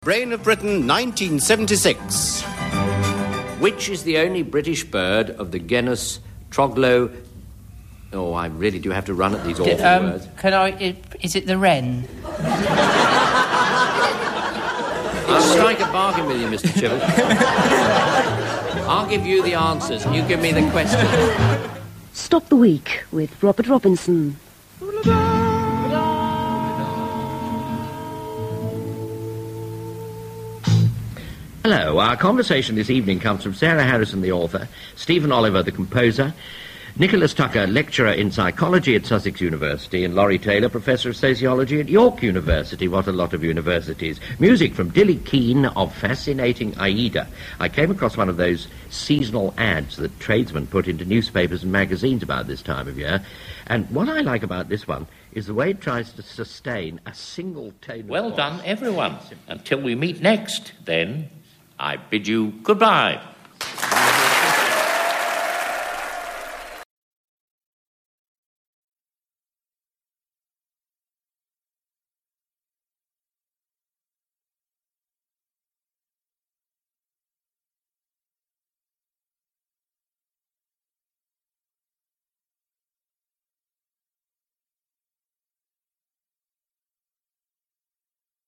Some voices are calming. Their very sound suggests long Summer evenings, roses round the door and a rustic, polite England where nothing could possibly come to any harm. Radio 4 is a frequent home of such voices, and maybe few better than Robert Robinson.